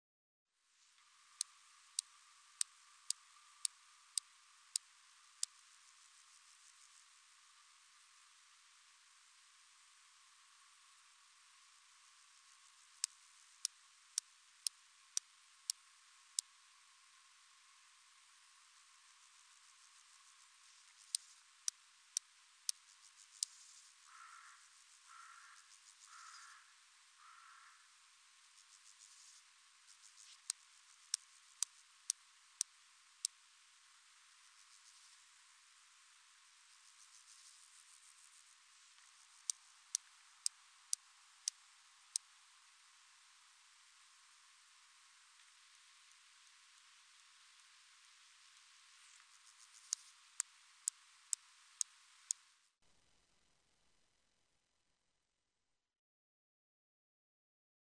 Sumpgræshoppe - Stethophyma grossum
Voksen
sumpgræshoppe.mp3